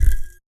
LabLaserStart.wav